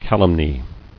[cal·um·ny]